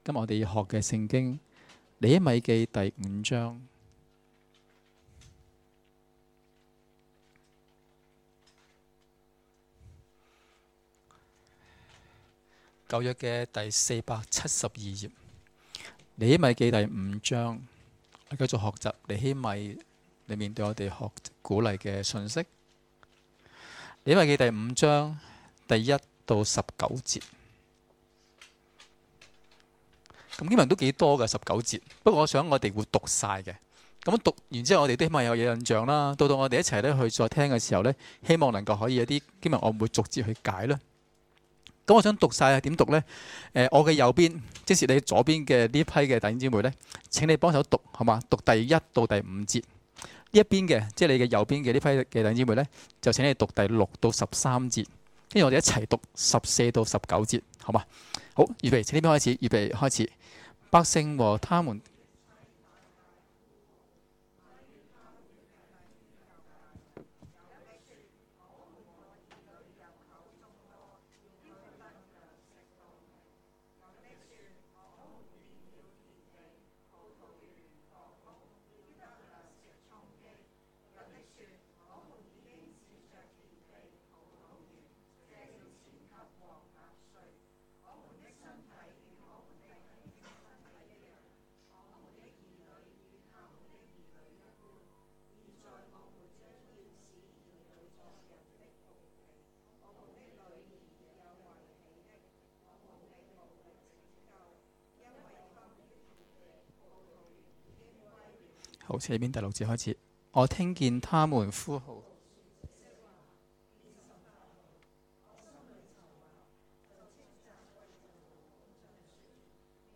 2017年3月4日及5日崇拜講道